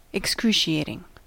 Ääntäminen
IPA : /ɪkˈskruːʃiːeɪtɪŋ/